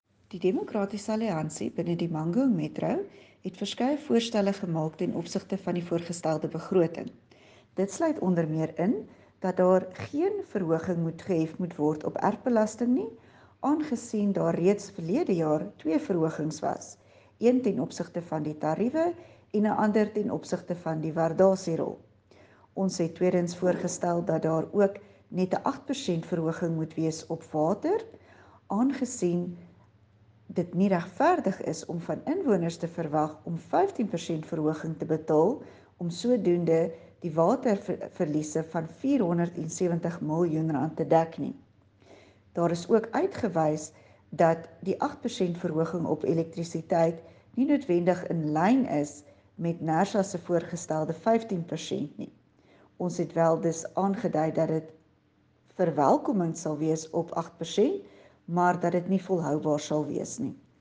Afrikaans soundbites by Cllr Dulandi Leech and